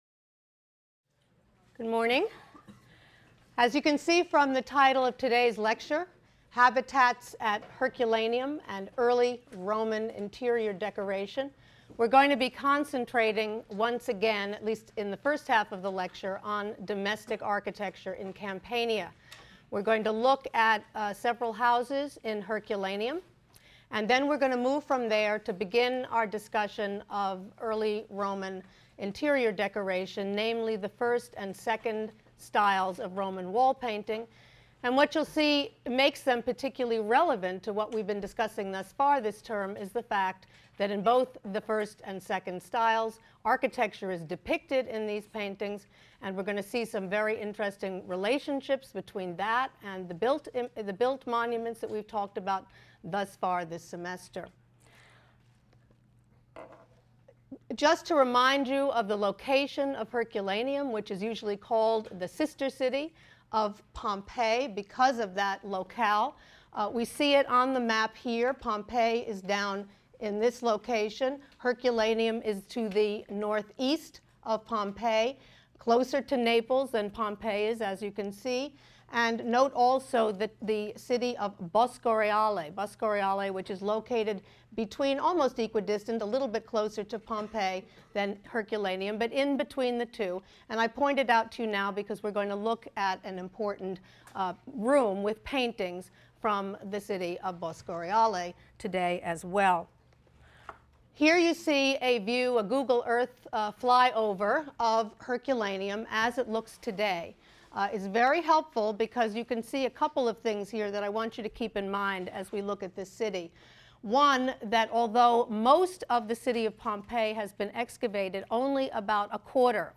HSAR 252 - Lecture 6 - Habitats at Herculaneum and Early Roman Interior Decoration | Open Yale Courses